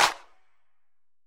Snares
CG_Snr (71).WAV